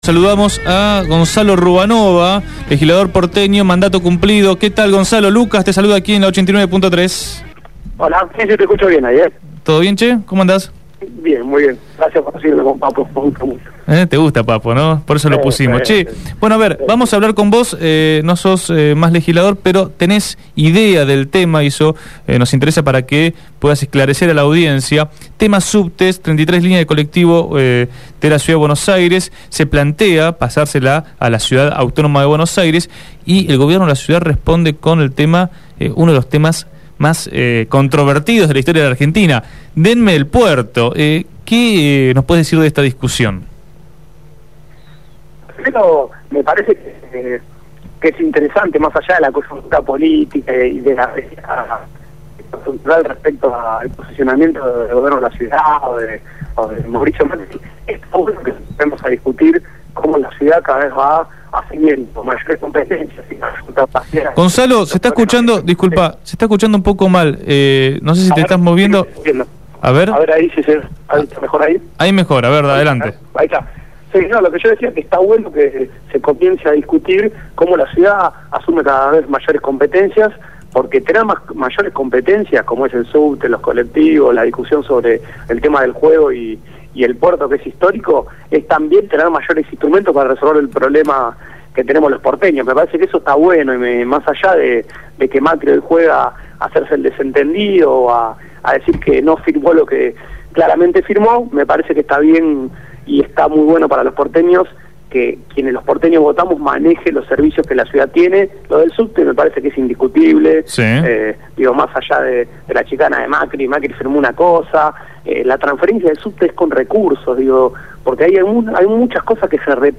Gonzalo Ruanova, legislador porteño con mandato cumplido, habló en el programa Punto de Partida sobre el traspaso de la red de subterráneos y las 33 líneas de colectivo a la Ciudad.